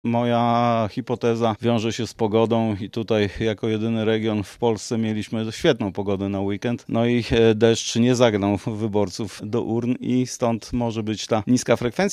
próbował wyjaśnić specjalista marketingu politycznego